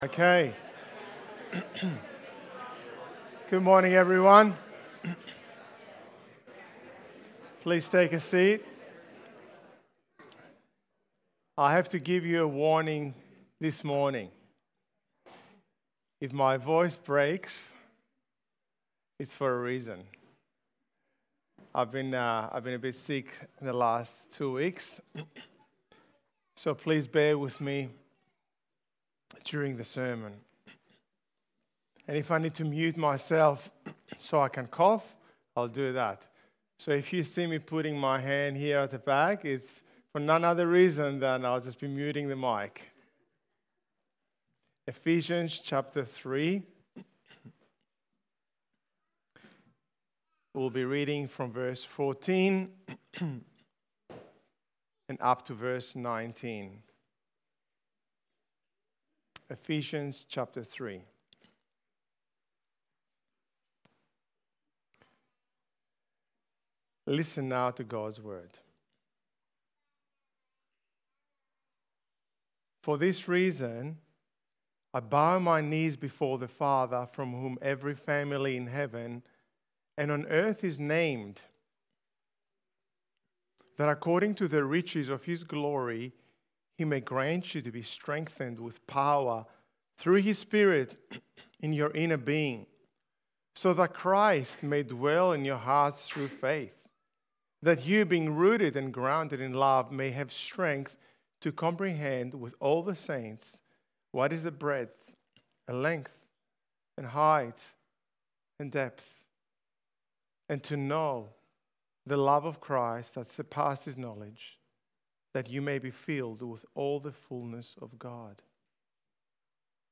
Ephesians Sermon Series